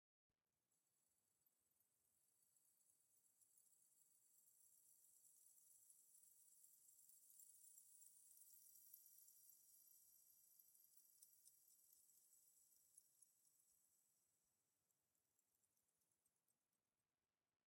firefly_bush3.ogg